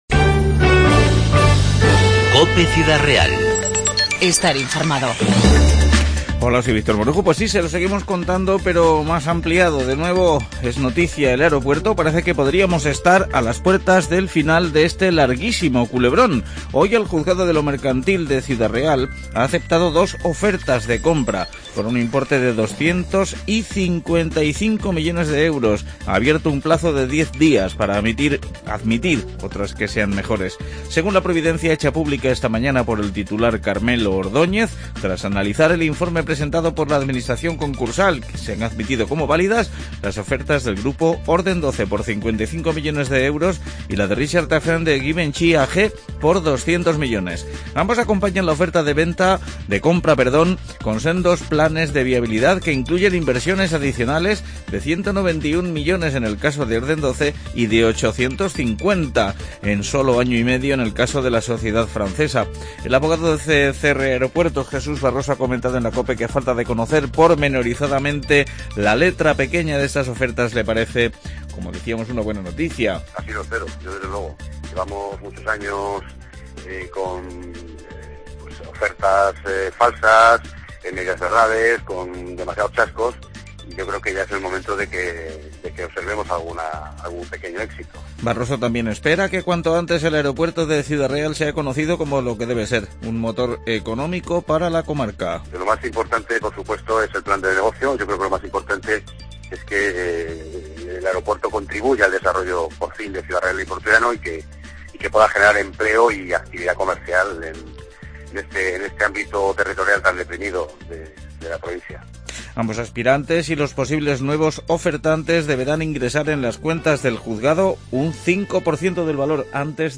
INFORMATIVO 2-3-16